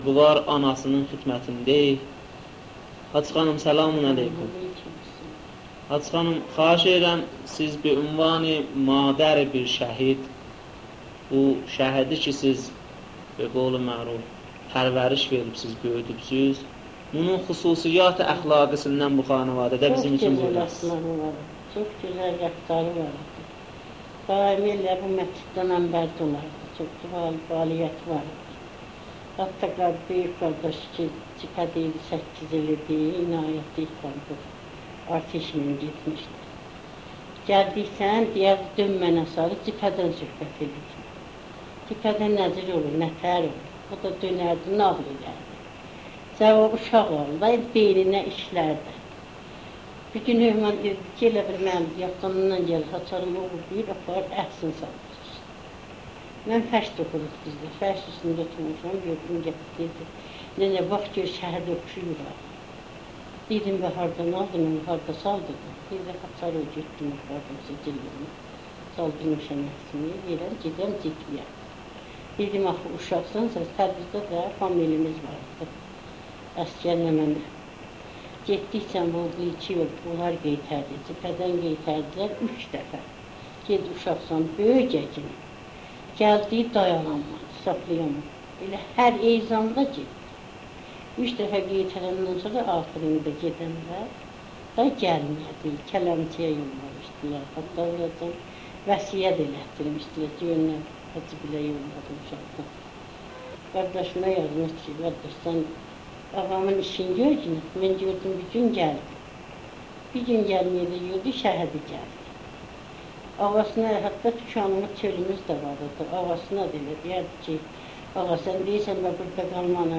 صوت / مصاحبه